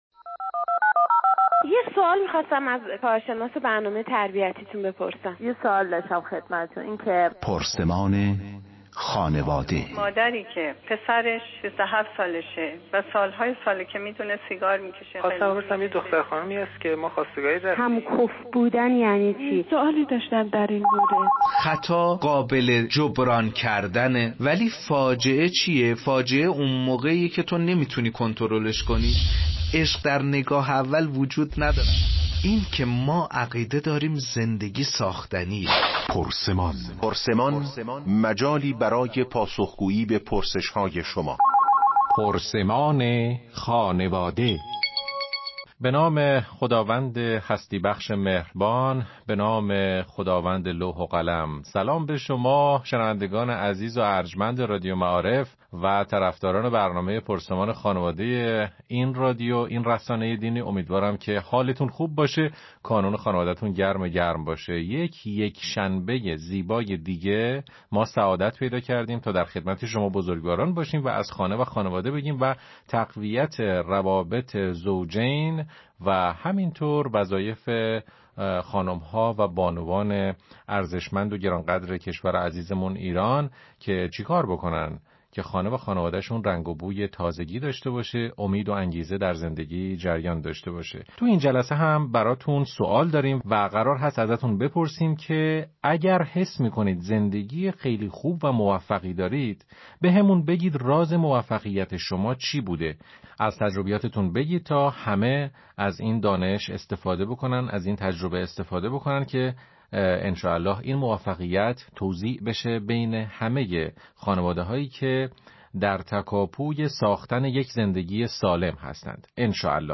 مشروح این گفت و گو بدین شرح است: